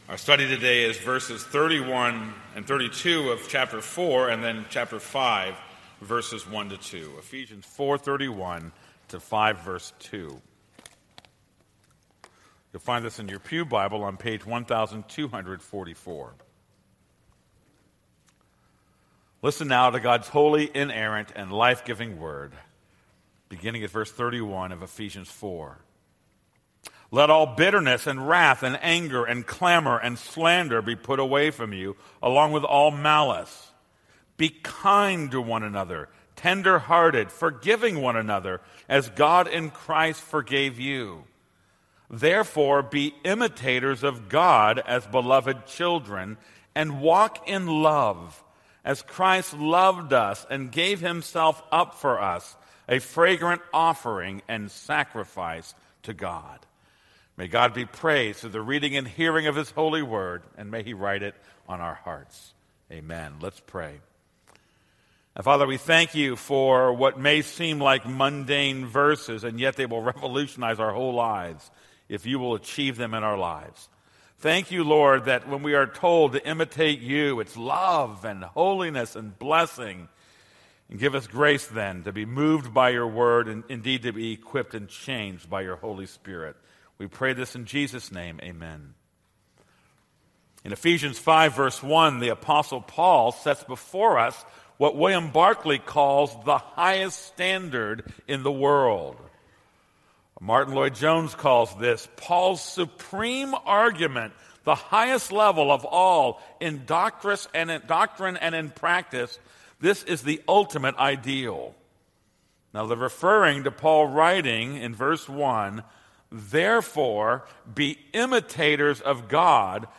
This is a sermon on Ephesians 4:31-5:2.